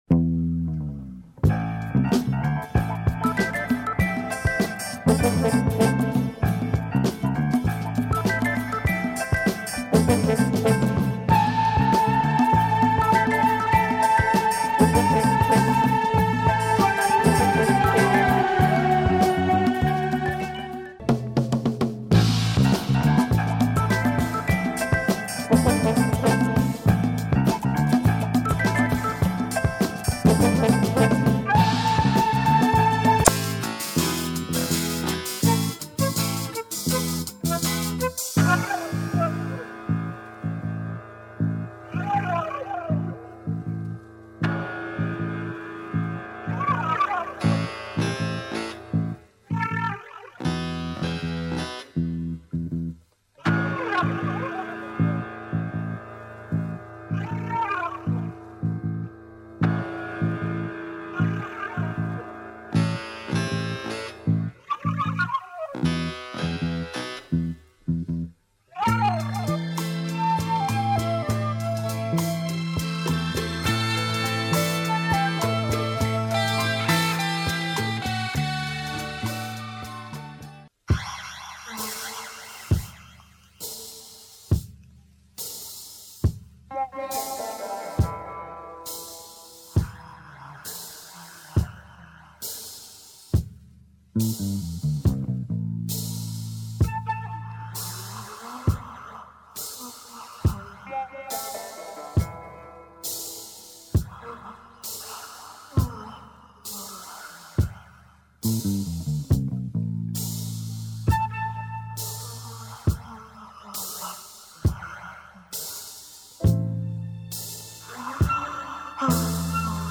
Very rare Italian soundtrack album